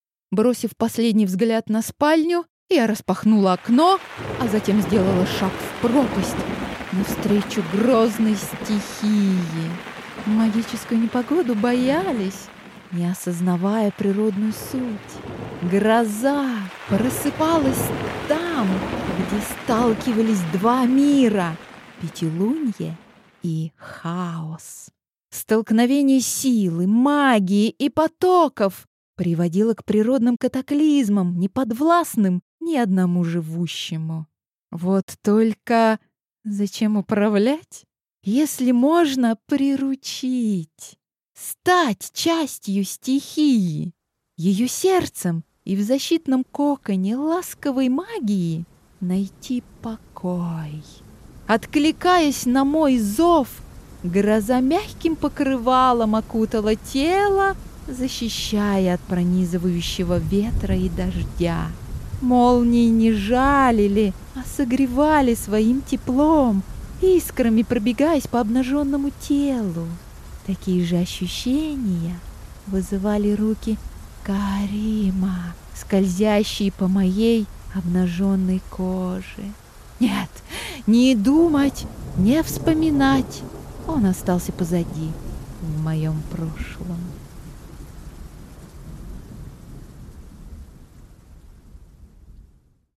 Аудиокнига Такие разные приключения | Библиотека аудиокниг